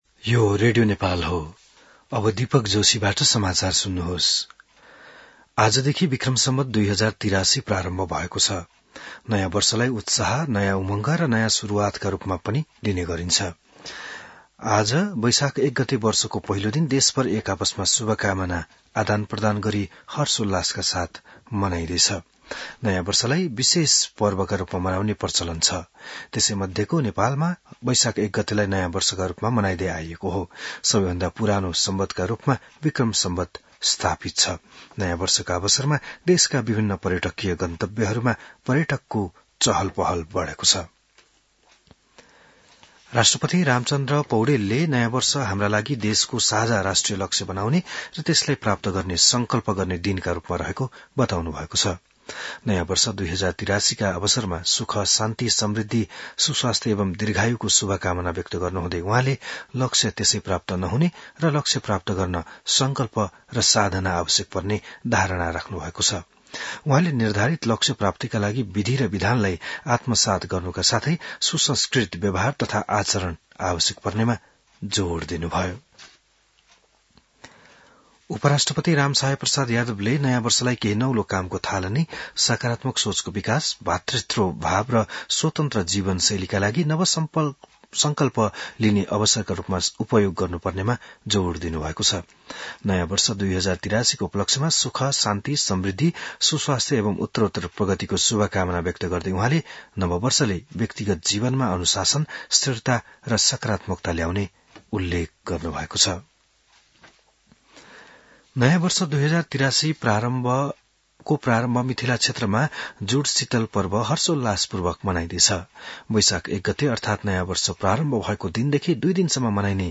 बिहान १० बजेको नेपाली समाचार : १ वैशाख , २०८३